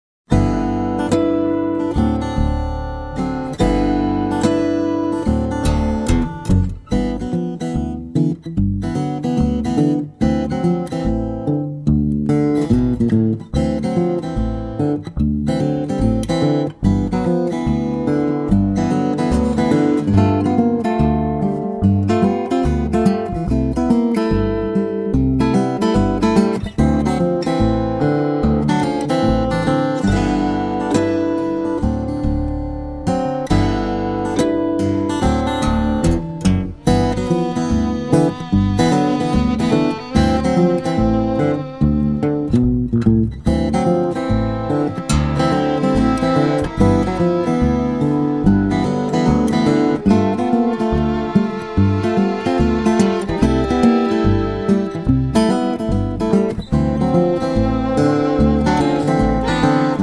fingerstyle instrumentals
Guitar